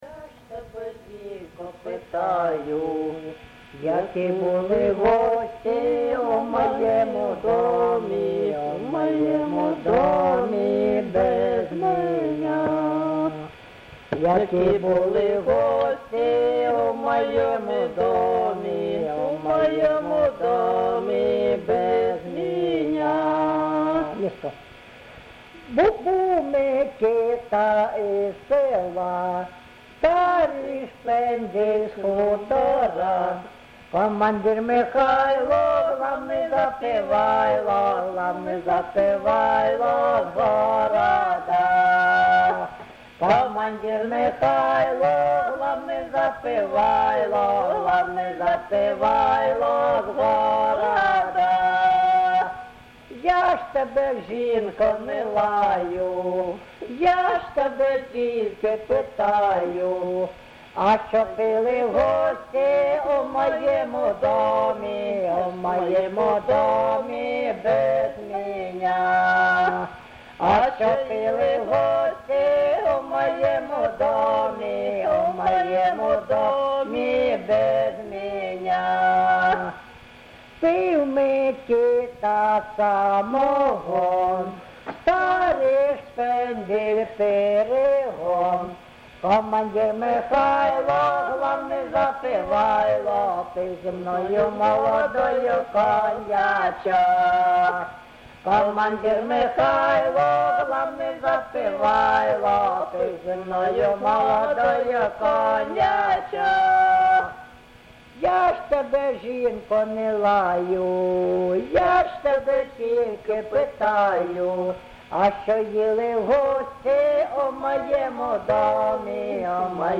ЖанрЖартівливі
Місце записум. Антрацит, Ровеньківський район, Луганська обл., Україна, Слобожанщина